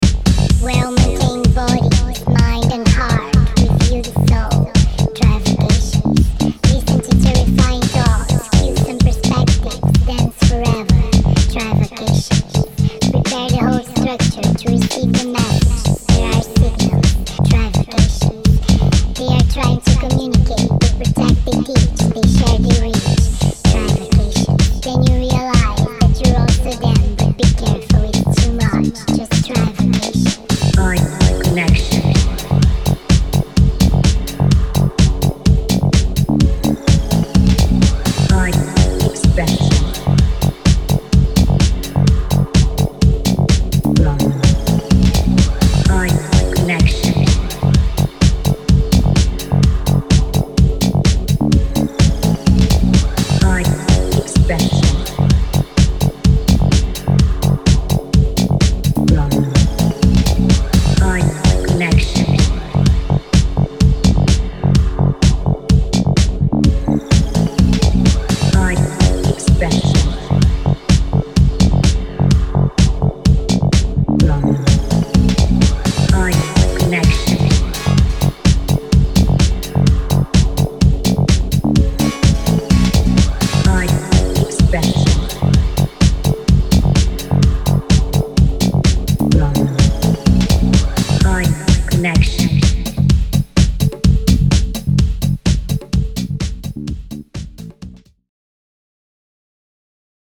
オールド・シカゴからの影響も感じるフローティンなアシッド・ハウス